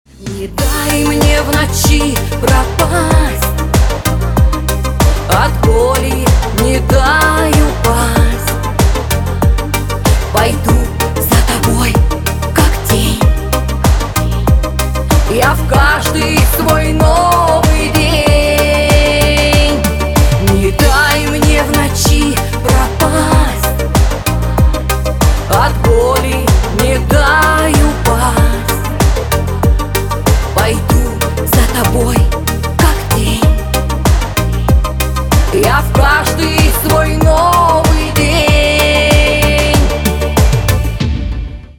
Рингтоны шансон песен
• Качество: Хорошее
• Песня: Рингтон, нарезка